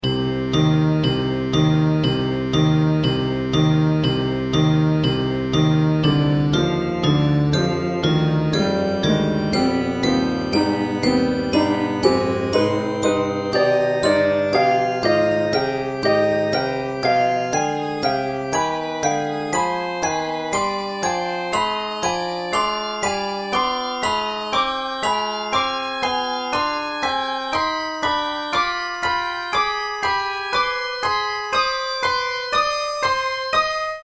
Key: C Harmonic Scale Range: 3 Octaves Track Tempo: 2x
Electric Guitar
Church Organ
Glockenspiel
Piano
Harp
Marimba